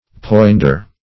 Search Result for " poinder" : The Collaborative International Dictionary of English v.0.48: Poinder \Poind"er\ (-[~e]r), n. 1. The keeper of a cattle pound; a pinder.